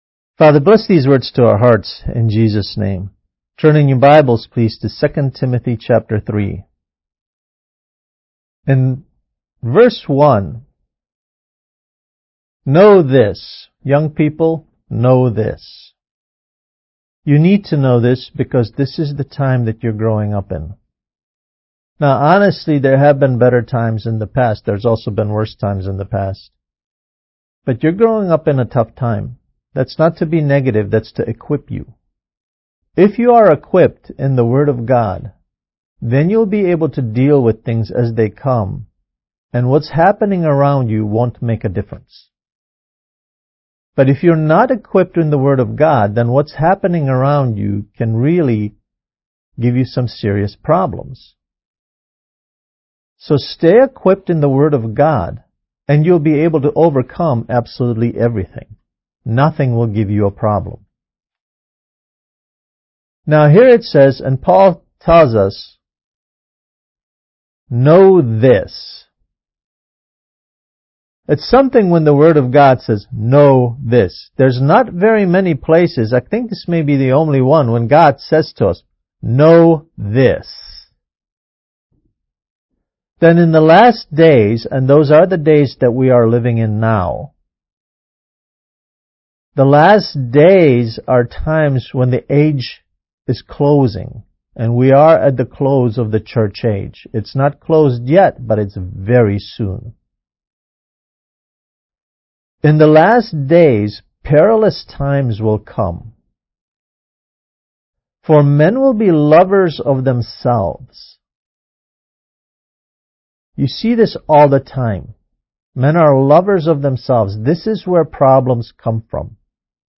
Kids Message: Finding the Right Way